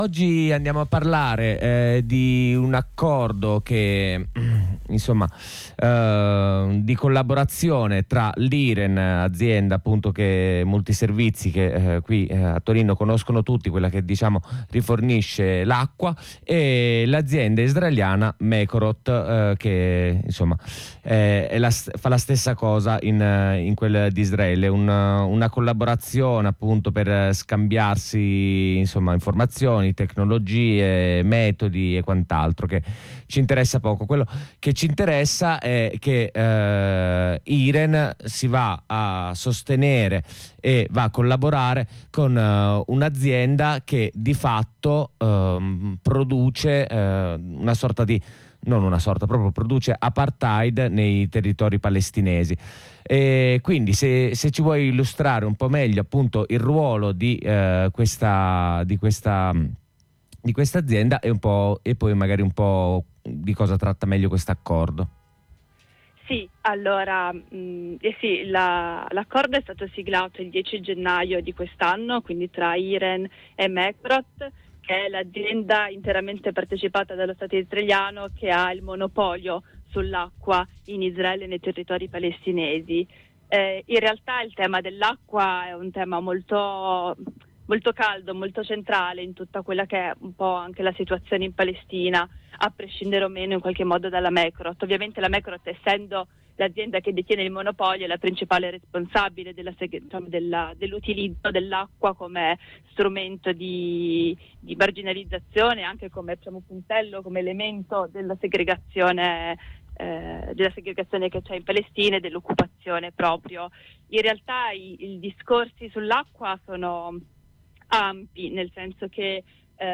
Questa società gonfia i prezzi delle forniture ai territori palestinesi o li lascia a secco in caso di siccità o come attacco politico. Ne parliamo con una compagna della rete BDS Torino in previsione delle mobilitazioni che si terranno in città.